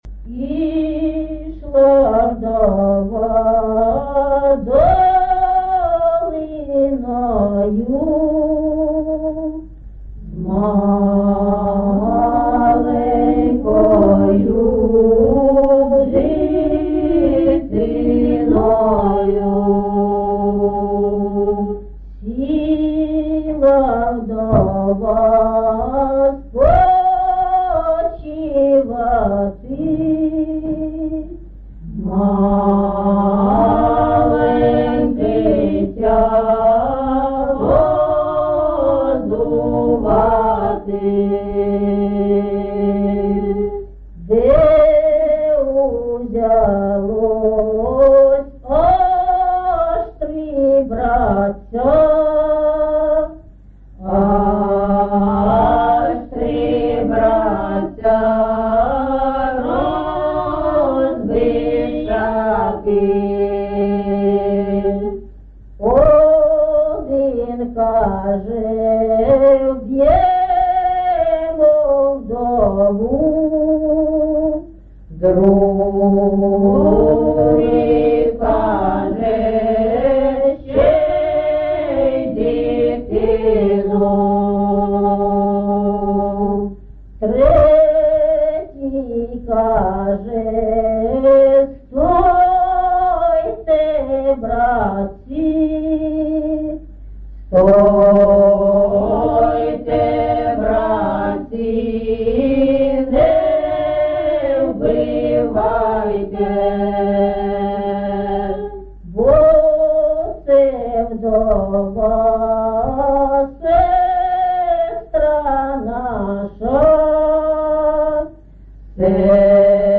ЖанрПісні з особистого та родинного життя, Балади
Місце записум. Єнакієве, Горлівський район, Донецька обл., Україна, Слобожанщина